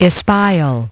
Транскрипция и произношение слова "espial" в британском и американском вариантах.